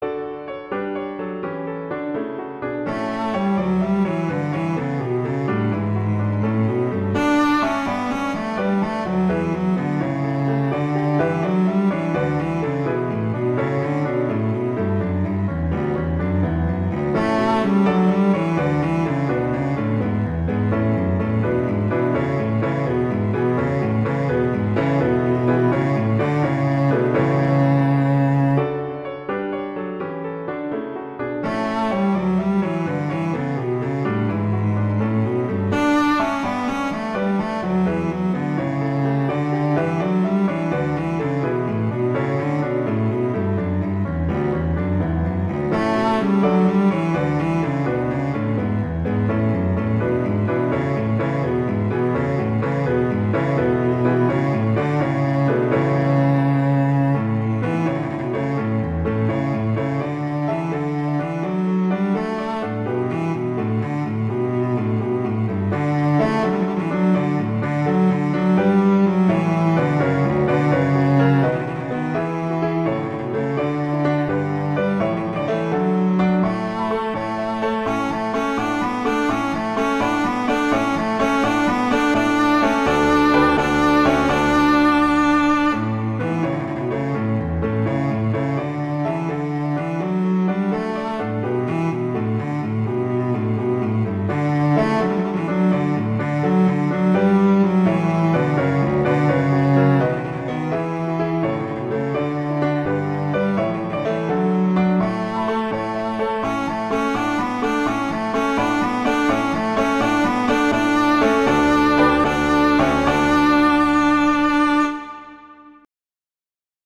classical, wedding, traditional, easter, festival, love
D major